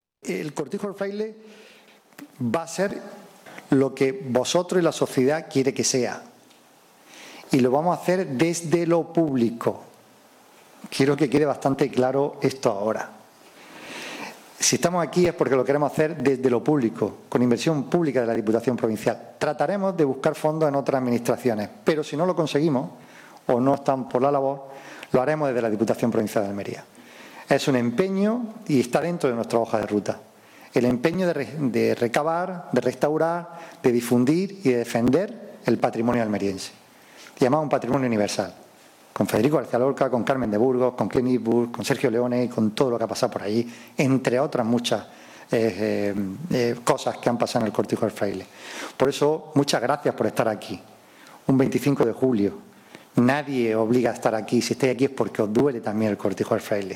Representantes de instituciones, empresas, entidades y asociaciones de carácter cultural o medioambiental han participado en esta jornada que se ha llevado a cabo en la Sala de Exposiciones de Rodalquilar.
25-07_cortijo_del_fraile_presidente_sonido_bueno-1.mp3